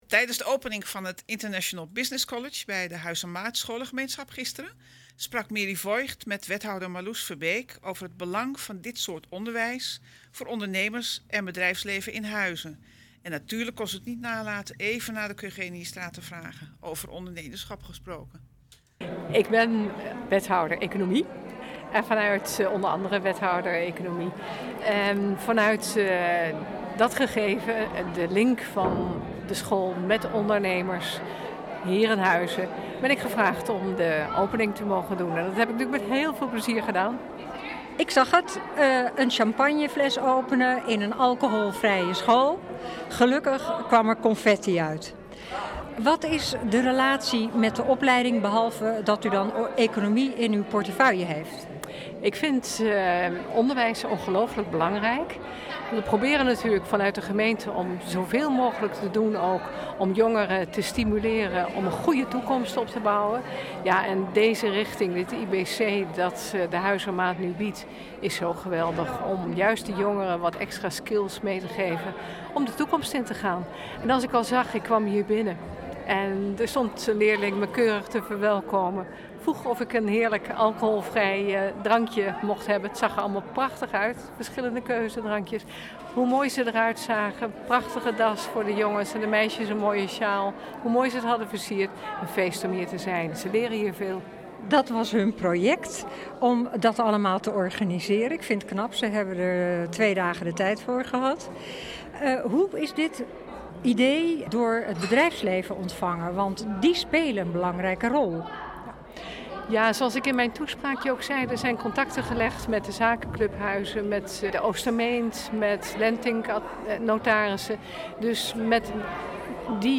Toelichting van Wethouder Marlous Verbeek over het belang van het International Business College en het ondernemerschap in Huizen.